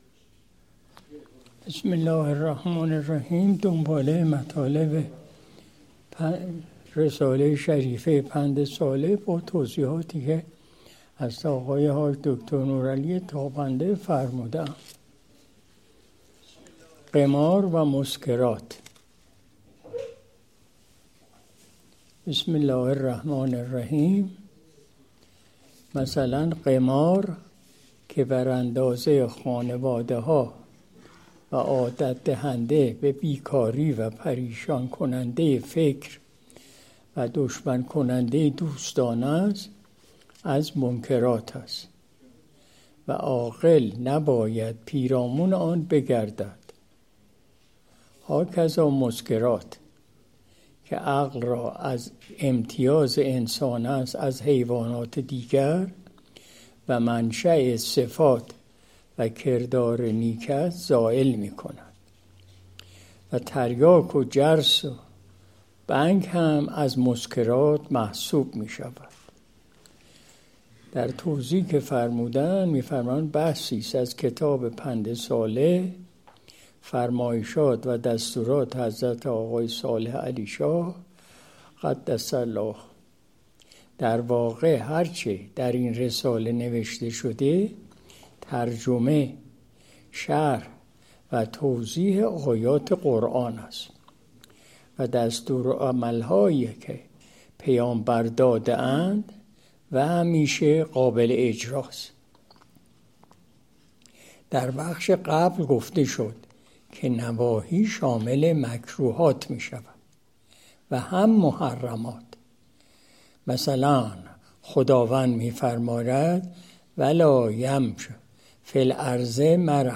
قرائت شرح رساله شریفه پندصالح از روی فرمایشات حضرت آقای مجذوبعلیشاه (ره) – قمار و مسکرات (قسمت ۱)